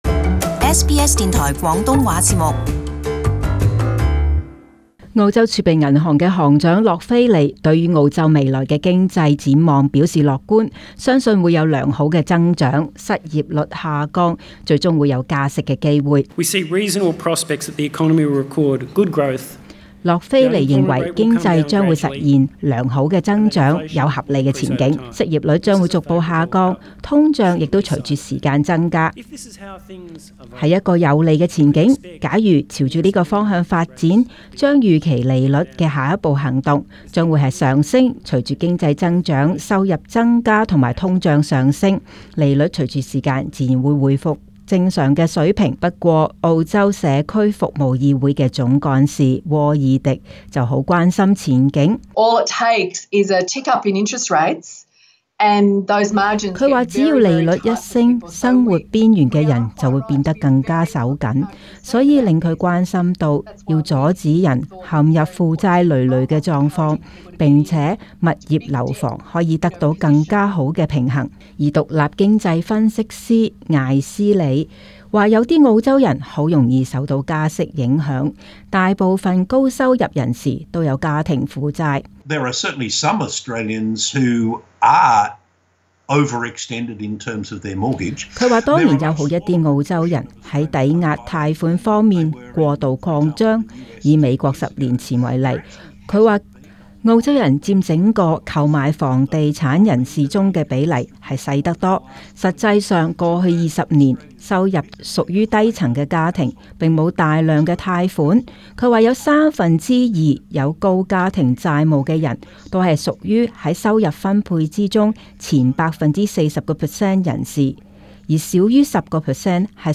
【時事報導】澳洲經濟展望